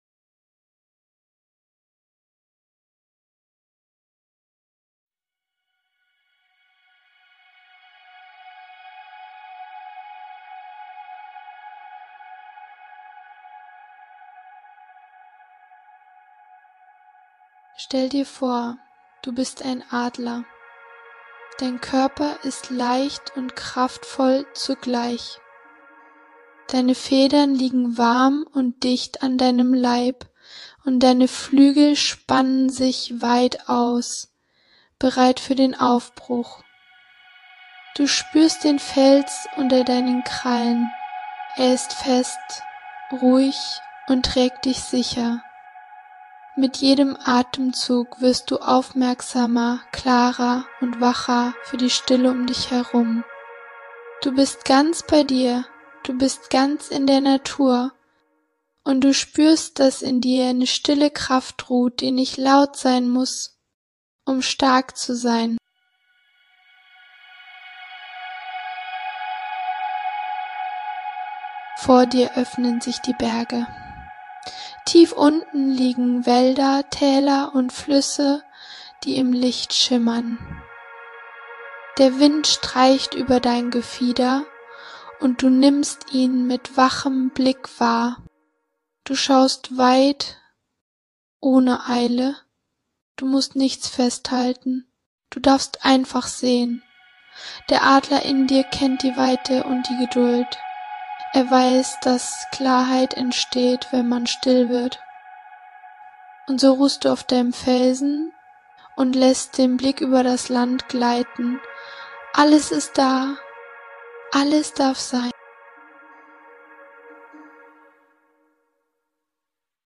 Diese 25-minütige geführte Meditation schenkt dir Freiheit, Achtsamkeit, Erdverbundenheit und reine Lebensfreude inmitten der wilden Natur.
Sanfte Bergwind-Sounds inklusive.